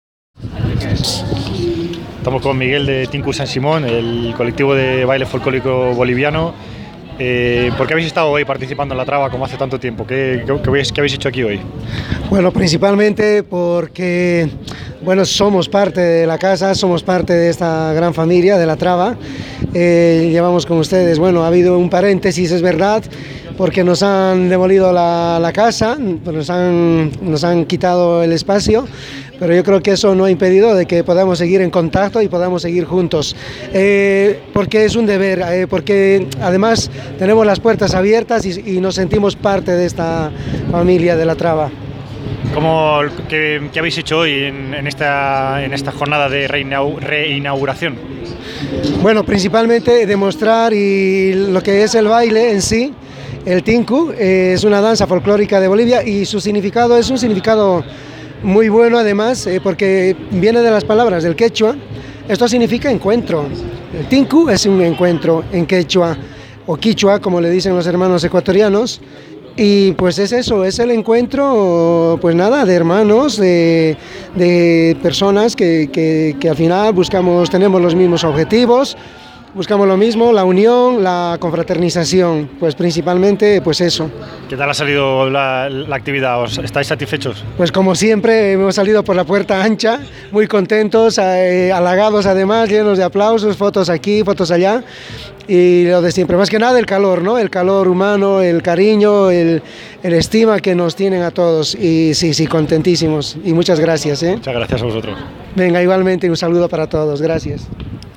Entrevistas durante la re-inauguración del CSO La Traba
Entrevista